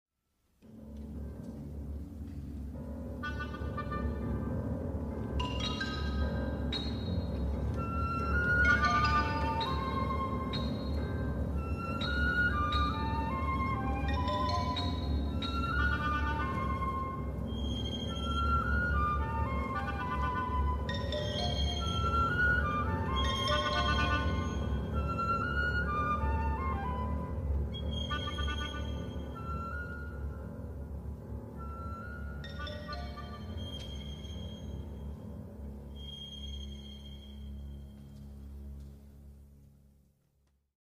Pomimo tego jakość dźwięku jest znakomita – jest on bardzo selektywny, bardziej miękki i zróżnicowany pod względem kolorystycznym niż ten w nagraniu Kurtza, co sprawia że jest też znacznie bardziej zmysłowy.
A szkoda, bo to drapieżna, ostra i dynamiczna interpretacja.
Usłyszymy tu wyraźnie dźwięki fortepianu w wysokim rejestrze, wtręty oboju i rożka angielskiego, krótkie motywy ksylofonu czy ostro brzmiące wejścia smyczków:
Leopold Stokowski, New York Philharmonic Orchestra, 1958, 14:09 (S), Everest